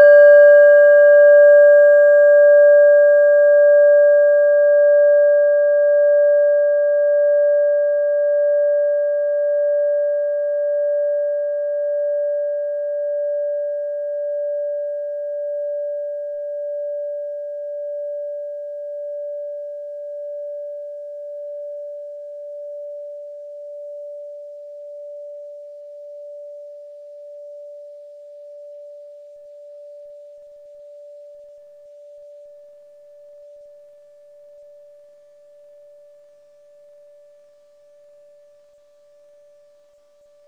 Kleine Klangschale Nr.19 Bengalen, Planetentonschale: Sarosperiode
(Ermittelt mit dem Minifilzklöppel)
Der Klang einer Klangschale besteht aus mehreren Teiltönen.
Die Klangschale hat bei 479.49 Hz einen Teilton mit einer
Die Klangschale hat bei 483.88 Hz einen Teilton mit einer
Signalintensität von 100 (stärkstes Signal = 100) :
In unserer Tonleiter liegt dieser Ton nahe beim "H".
kleine-klangschale-19.wav